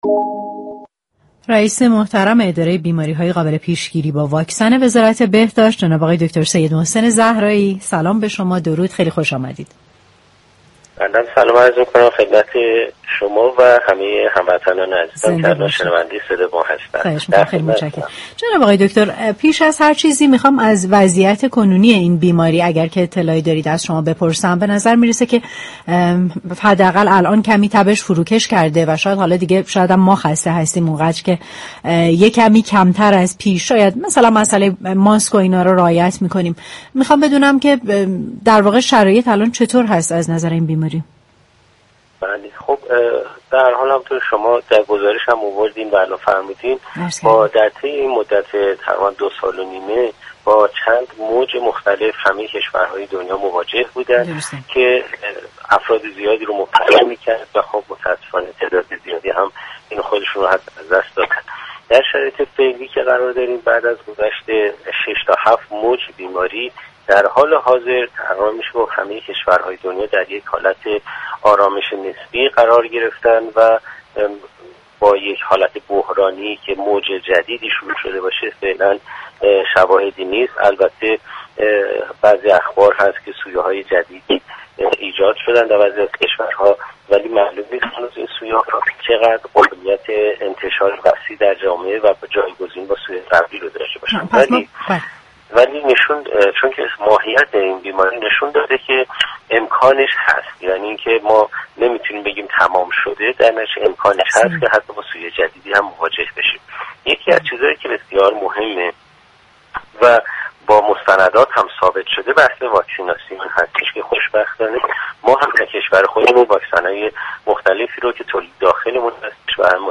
به گزارش پایگاه اطلاع رسانی رادیو تهران، سید محسن زهرایی رئیس اداره بیماری های قابل پیشگیری با واكسن مركز مدیریت بیماریهای واگیر وزارت بهداشت در گفت‌وگو با تهران من رادیو تهران گفت: پس از گذشت حدود 3 سال از شیوع بیماری كرونا و مواجهه با 6 تا 7 موج كرونا، تمام كشورهای دنیا در یك آرامش نسبی قرار دارند و شواهدی مبنی بر وقوع موج جدید را مشاهده نمی‌كنیم.